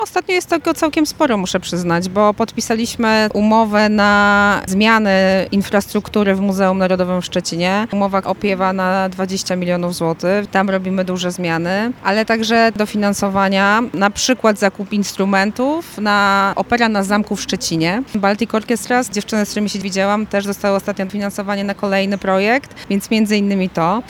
Wśród największych projektów, które zyskają finansowanie, znajduje się modernizacja Muzeum Narodowego w Szczecinie. O szczegółach mówi Marta Cienkowska, wiceminister Kultury i Dziedzictwa Narodowego: